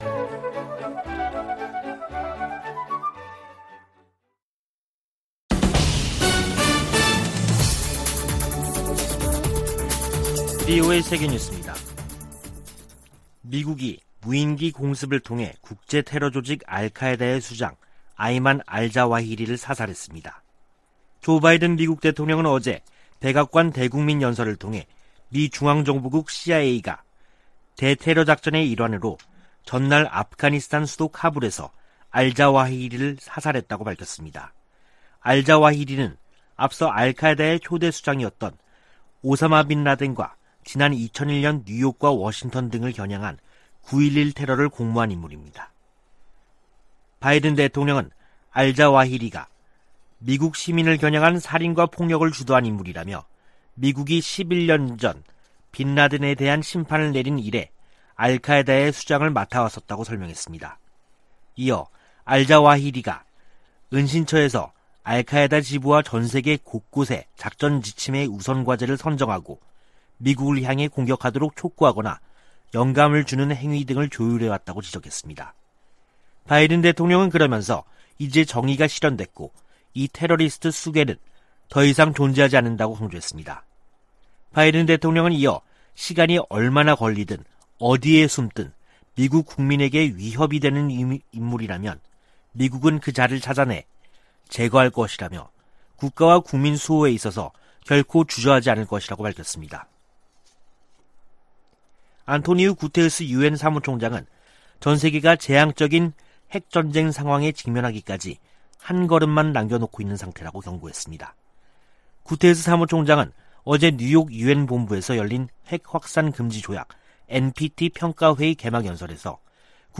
VOA 한국어 간판 뉴스 프로그램 '뉴스 투데이', 2022년 8월 2일 3부 방송입니다. 조 바이든 미국 대통령은 핵확산금지조약(NPT) 평가회의를 맞아 비확산 체제 준수와 지지 입장을 재확인했습니다. 토니 블링컨 미 국무장관은 NPT 평가회의에서, 조약이 가중되는 압박을 받고 있다며 북한과 이란의 핵 개발 문제를 비판했습니다.